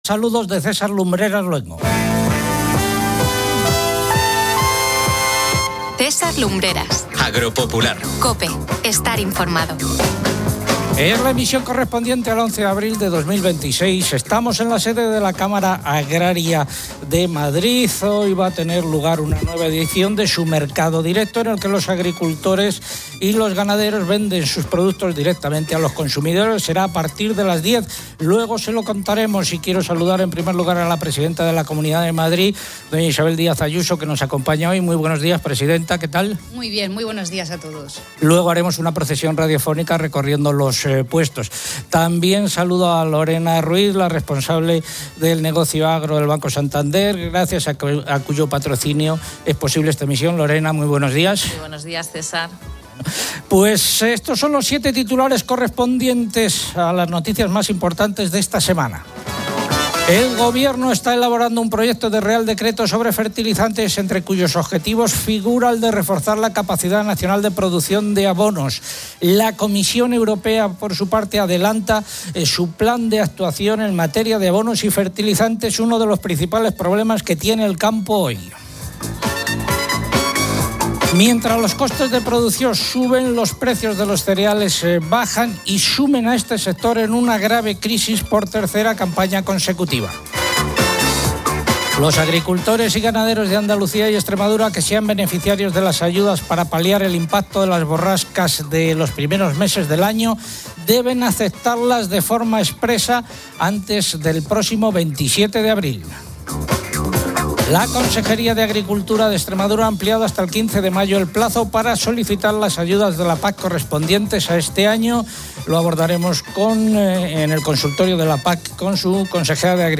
El programa se emite desde el mercado directo de la Cámara Agraria de Madrid, un evento que fomenta la venta directa de productos locales por parte de agricultores y ganaderos. La presidenta de la Comunidad de Madrid, Isabel Díaz Ayuso, presente en el evento, destaca el compromiso regional con el sector primario y la promoción de sus productos.